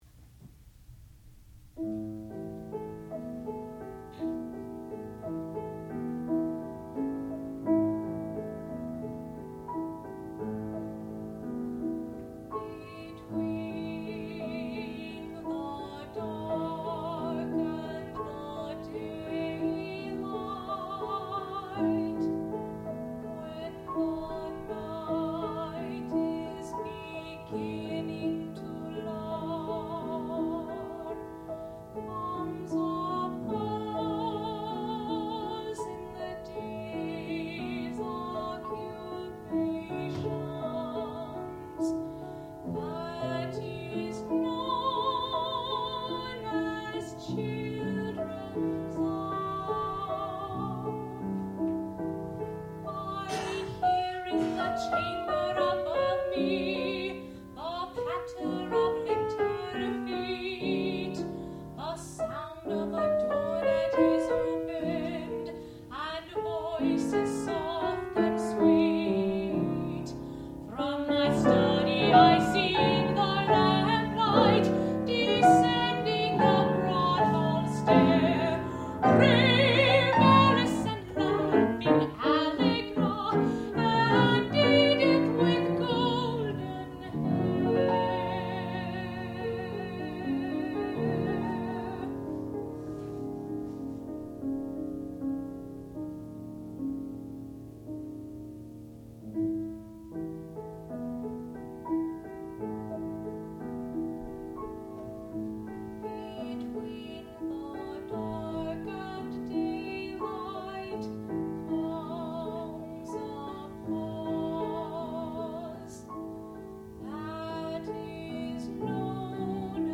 sound recording-musical
classical music
mezzo-soprano
piano
Qualifying Recital